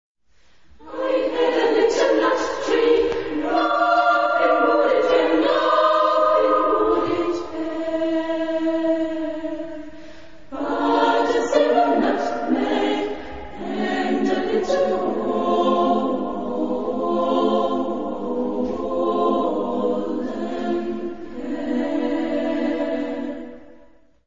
Genre-Stil-Form: Zyklus ; Chorlied ; weltlich
Chorgattung: SSAA  (4 Frauenchor Stimmen )
Tonart(en): frei
von Mädchenchor der Ulmer Spatzen gesungen
Aufnahme Bestellnummer: 6. Deutscher Chorwettbewerb, 2002